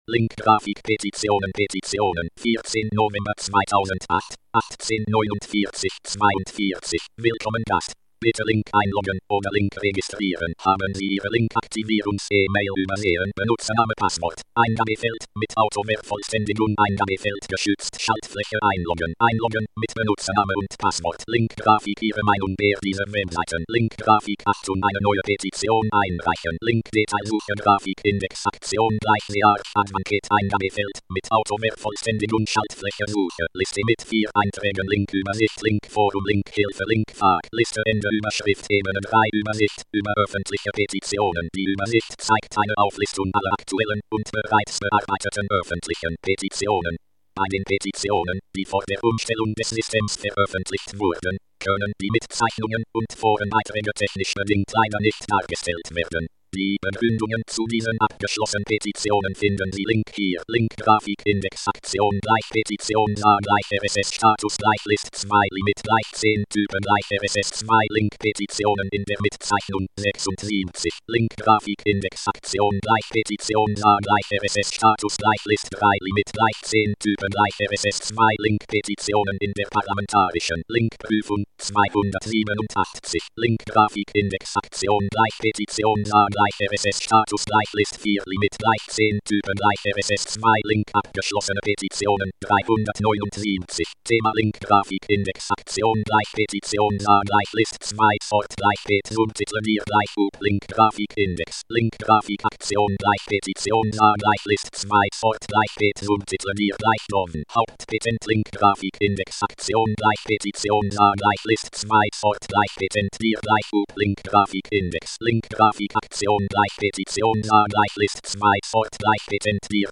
Als Ergänzung zu meiner Kritik an der Online-Petitions-Webseite des Bundestages habe ich ein paar Audio-Aufzeichnungen mit Screenreadern gemacht, die unten als MP3 verlinkt sind. Damit kann man hören, wie sich die Webseite für einen Blinden anhört.
Es ist deutlich hörbar, dass die e-Petitions-Webseite des Bundestages mit einem Screenreader kaum benutzbar ist.
01-Petitionsuebersicht--NVDA.mp3